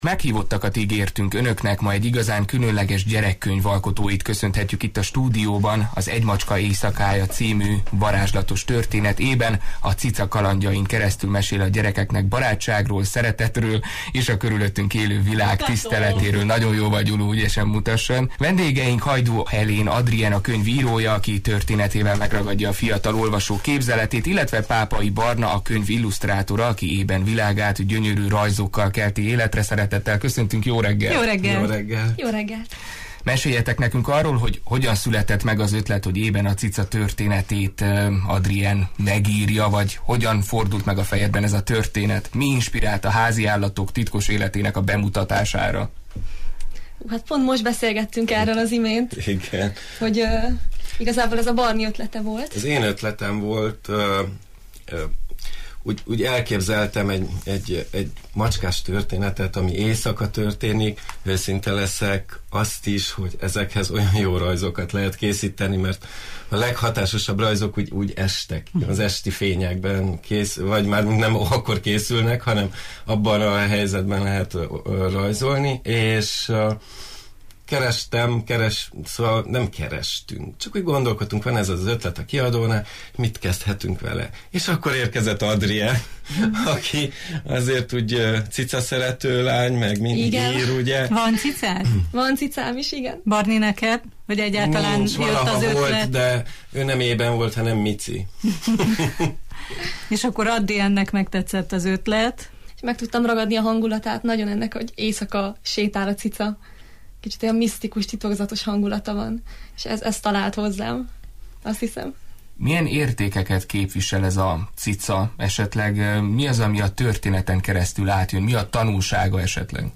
Ma egy igazán különleges gyerekkönyv alkotóit köszöntöttük a stúdióban! Az Egy macska éjszakája című varázslatos történet Ében, a cica kalandjain keresztül mesél a gyerekeknek barátságról, szeretetről és a körülöttünk élő világ tiszteletéről.